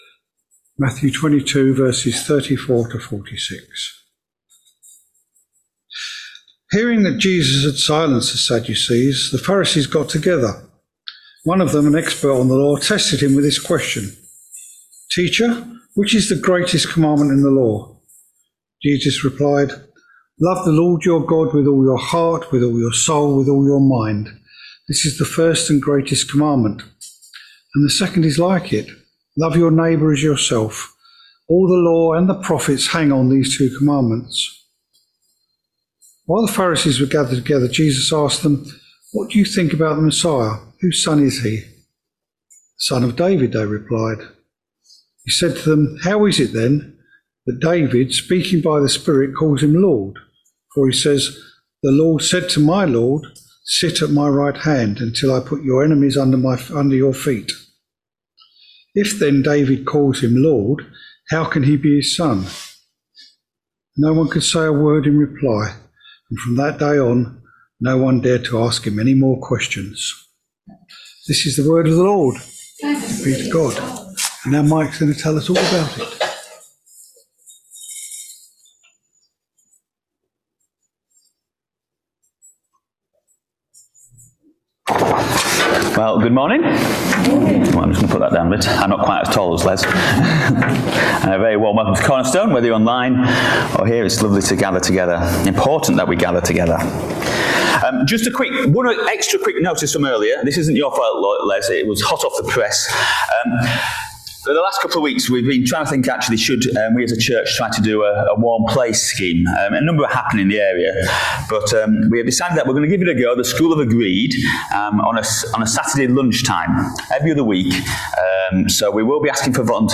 Matthew 22v34-46 Service Type: Sunday Morning Service Topics